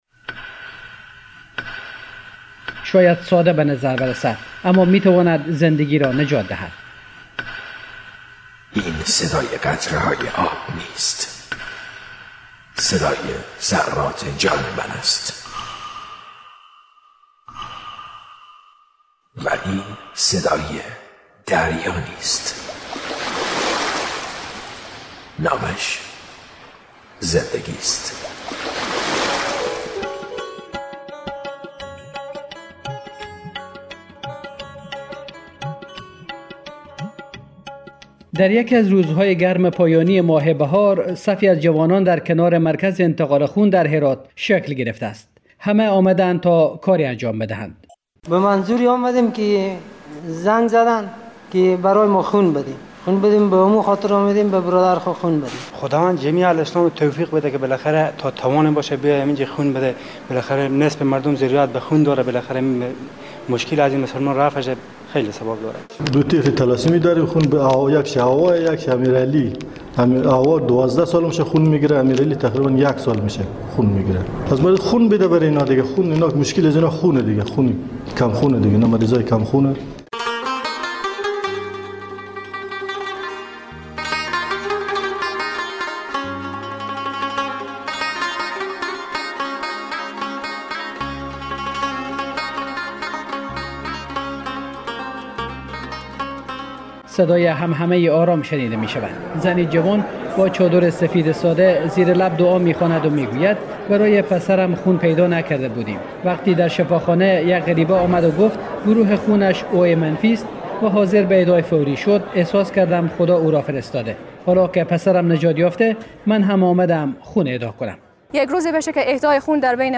مستند اهدای خون، اهدای زندگی